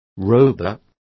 Complete with pronunciation of the translation of roebuck.